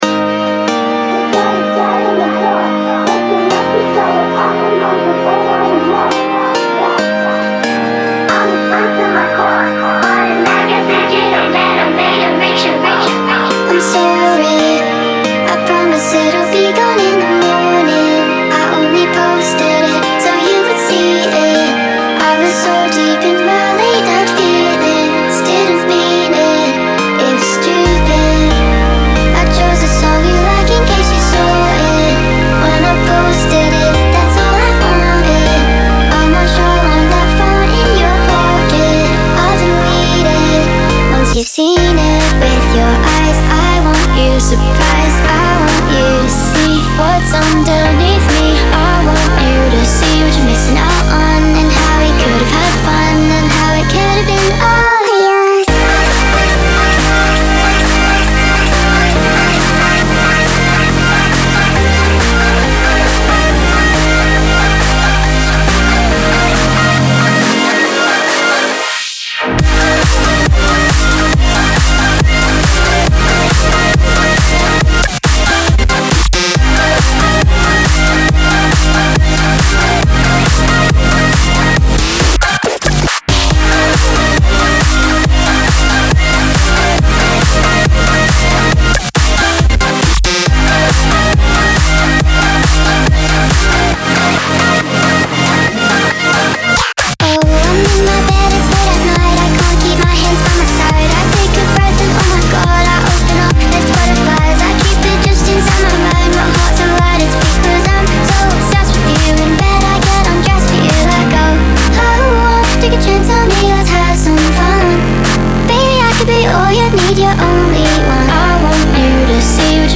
BPM138-138
Audio QualityPerfect (High Quality)
Full Length Song (not arcade length cut)